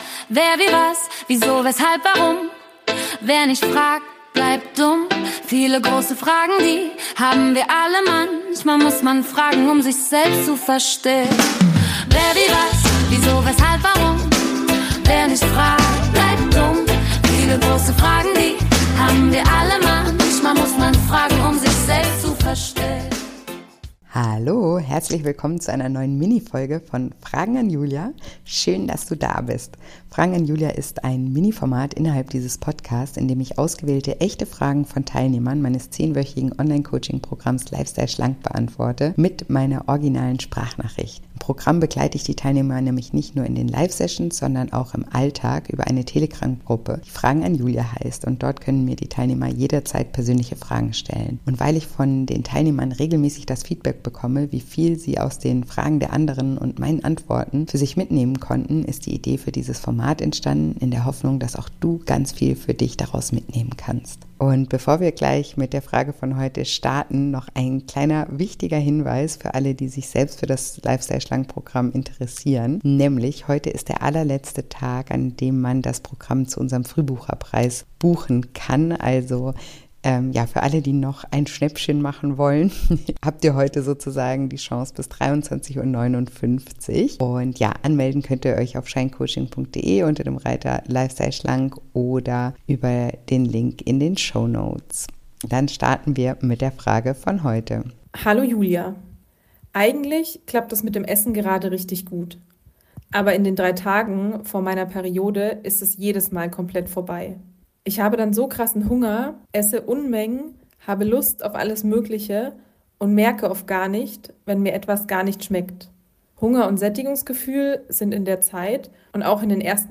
Bitte beachte: Es sind originale Sprachnachrichten aus dem Coaching-Alltag – die Audioqualität ist daher nicht immer wie gewohnt, der Inhalt dafür umso authentischer.